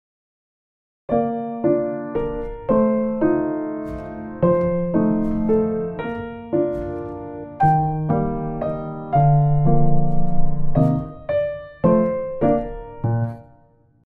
7級B/変ロ長調３拍子
2 伴奏形